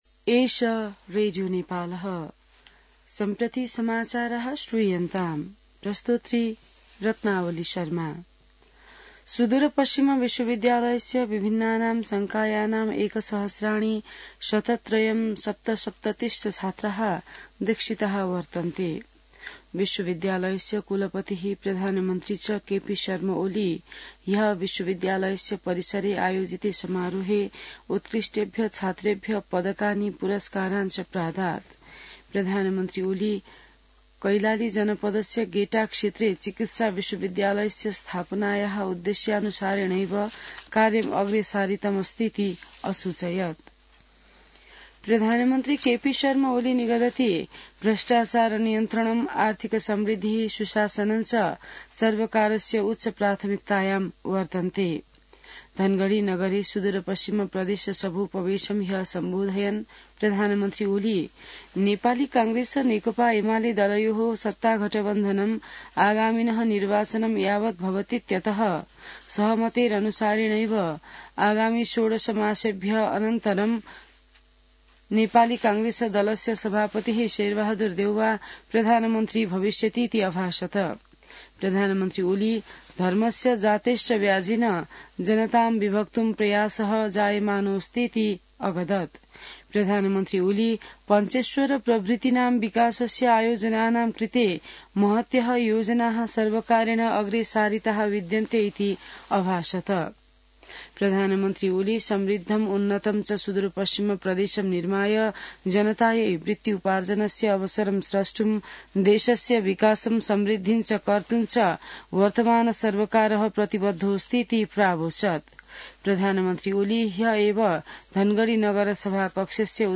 संस्कृत समाचार : २७ फागुन , २०८१